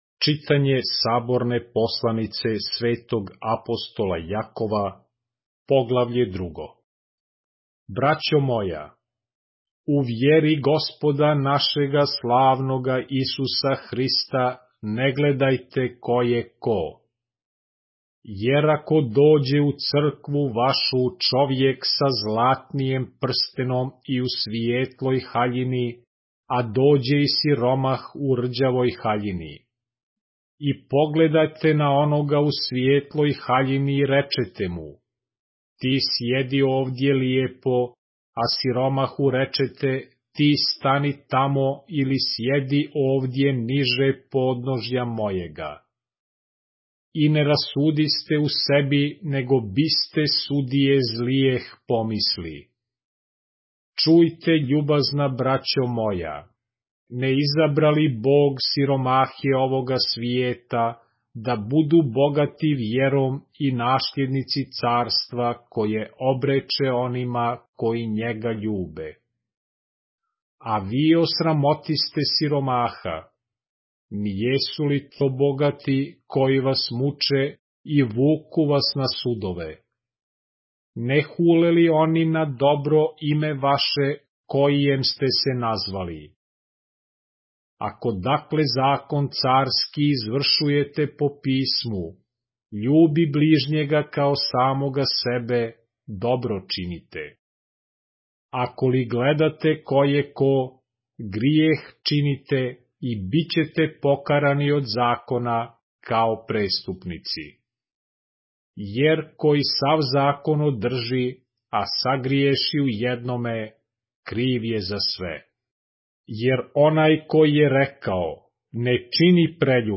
поглавље српске Библије - са аудио нарације - James, chapter 2 of the Holy Bible in the Serbian language